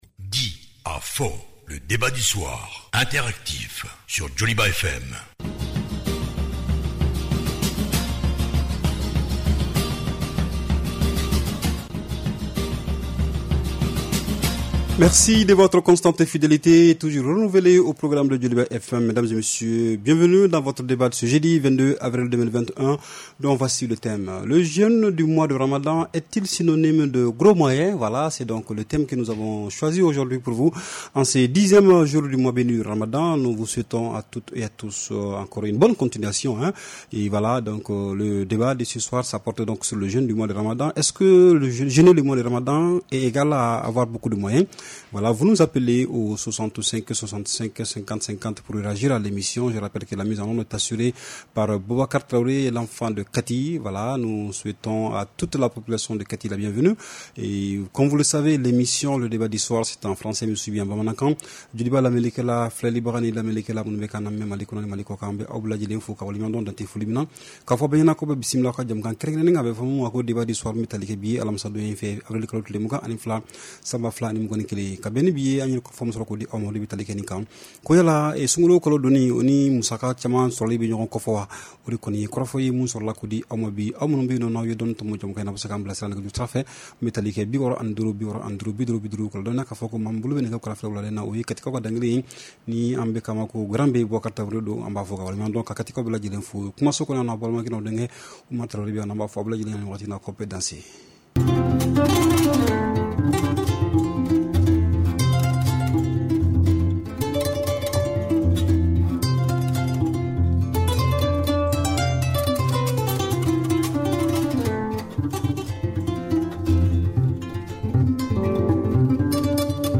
REPLAY 22/04 – « DIS ! » Le Débat Interactif du Soir